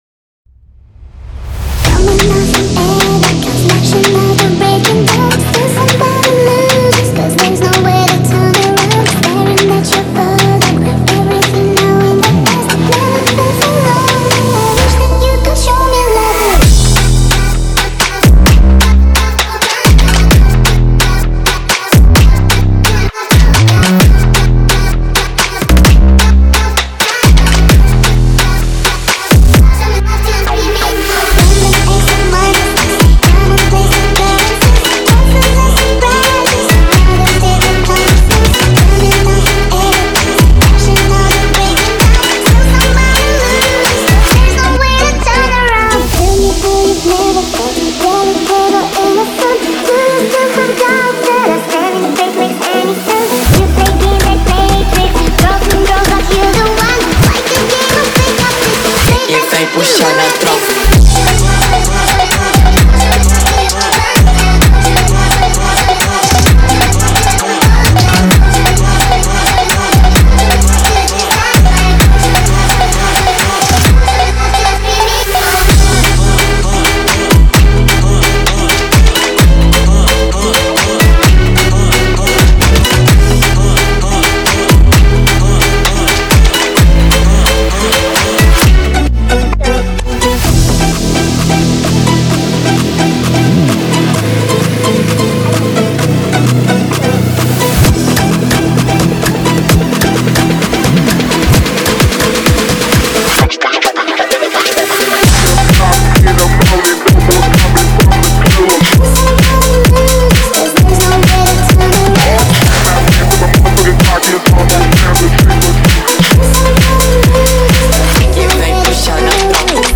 басовые треки